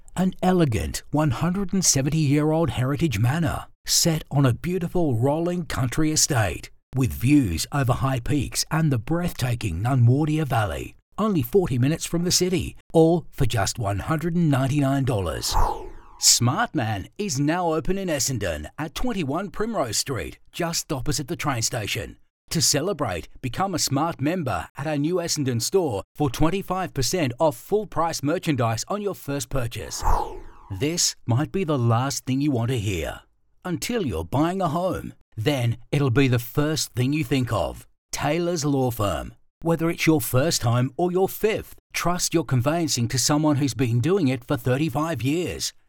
• Relaxed
• Middle age male voiceovers
• Custom Voice Booth
• Microphone Neumann TLM 103